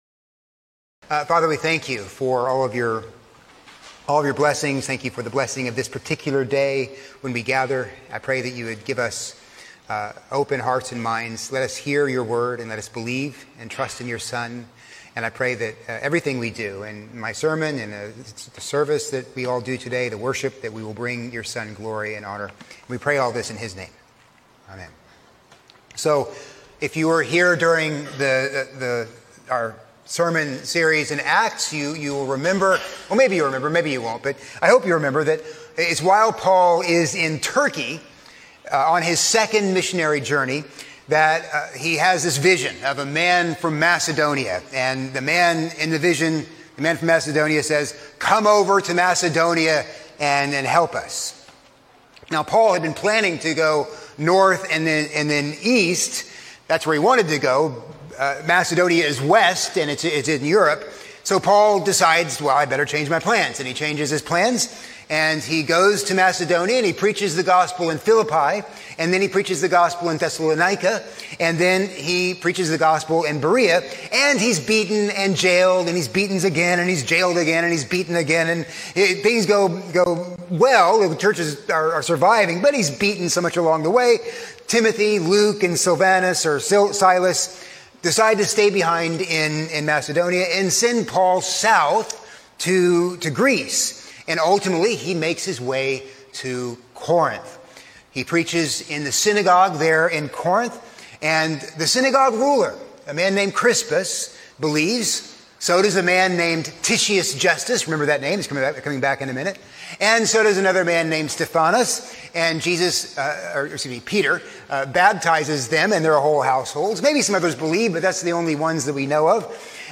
A sermon on II Corinthians 1:1-2